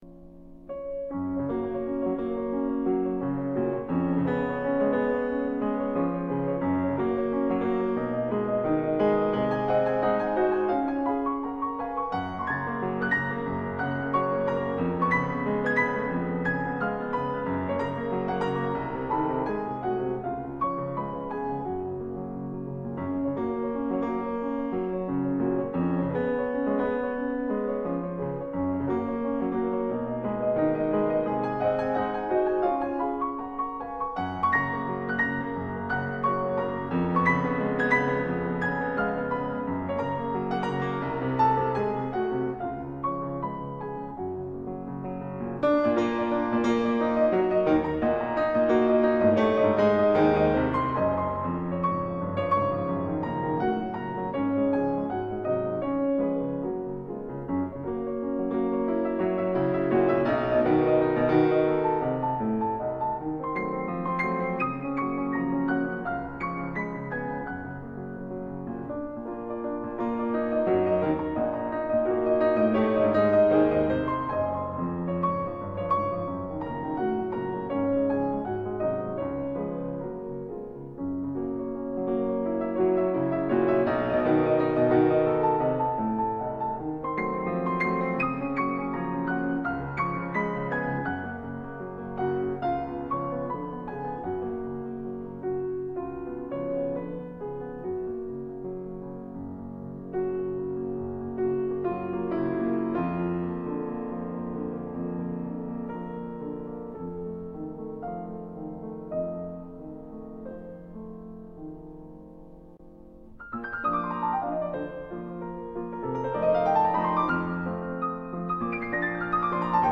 Franz Schubert's Impromptu in B-flat: Variations IV, V, and VI. These movements have awkward breaks if not played continuously, the others extract quite a bit better.